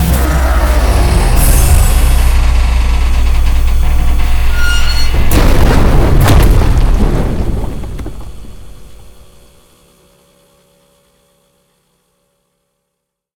die.ogg